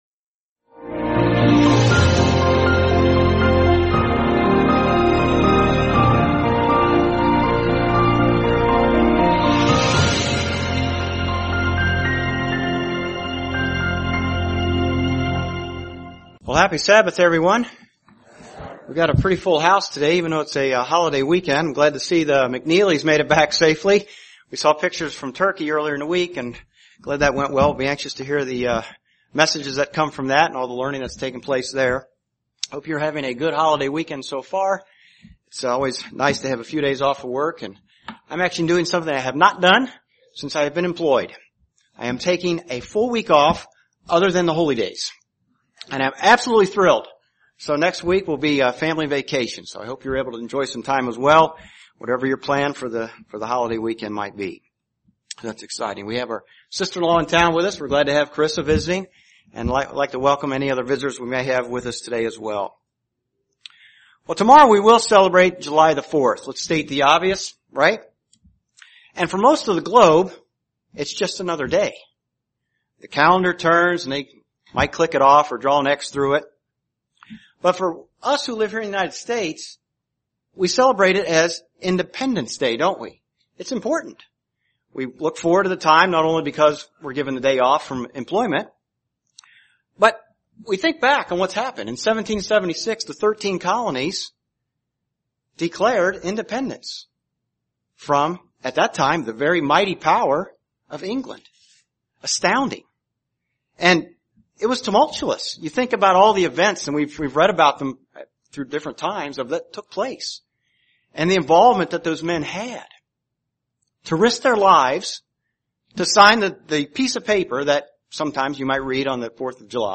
Sermons
Given in Indianapolis, IN